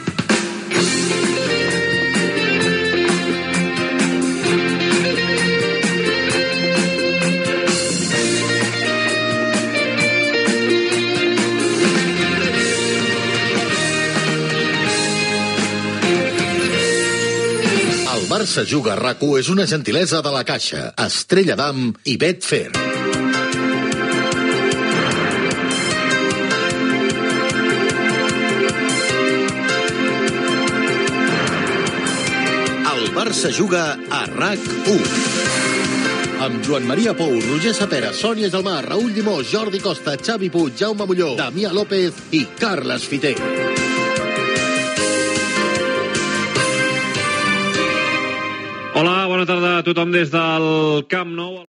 Sintonia de l'emissora, careta del programa amb els noms de l'equip i salutació inicial
Esportiu